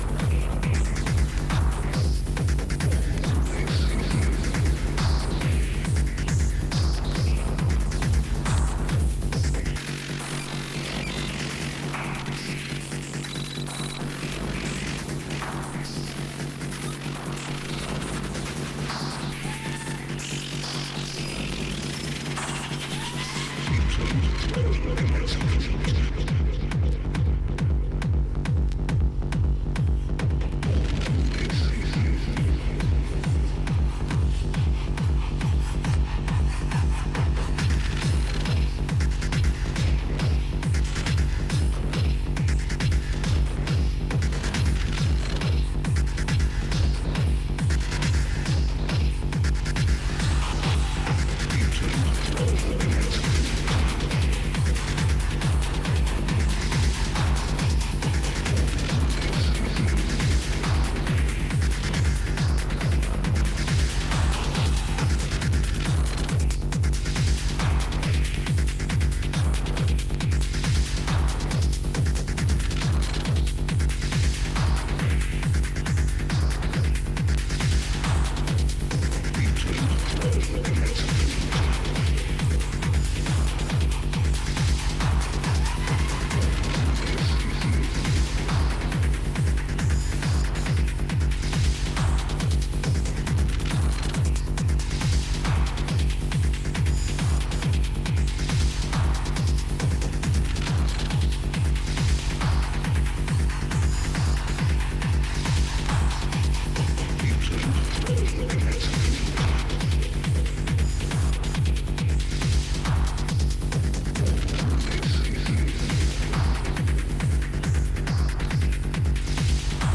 EBM/Industrial, Techno